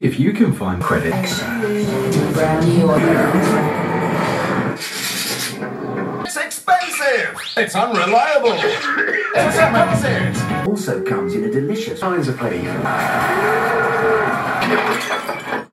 Adbreak blare
This sound was recorded for the Fantastical Reality Radio Show in assn. w. Mundane Appreciation, funded by the Sonic Arts Network, Radio Reverb and earshot